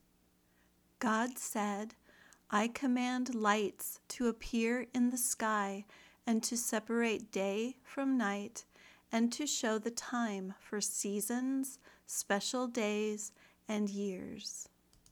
If you are learning American English, imitate her pronunciation the best you can.